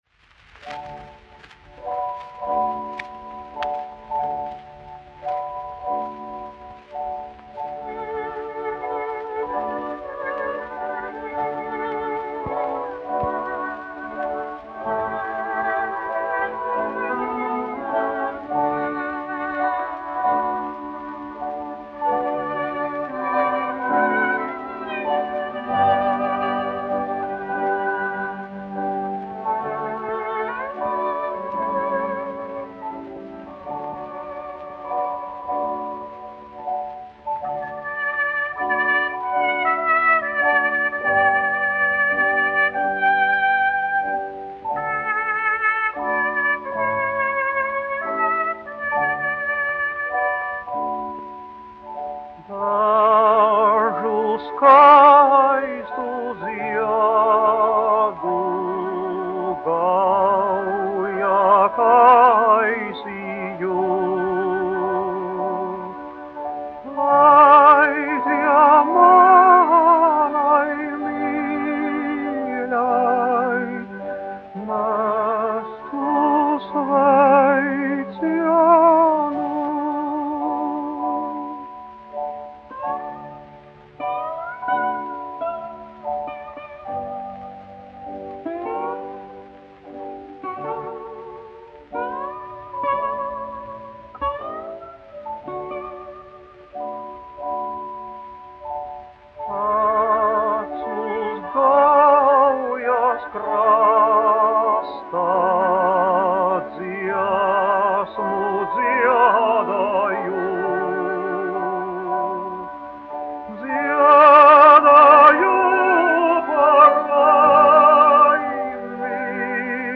1 skpl. : analogs, 78 apgr/min, mono ; 25 cm
Populārā mūzika -- Latvija
Latvijas vēsturiskie šellaka skaņuplašu ieraksti (Kolekcija)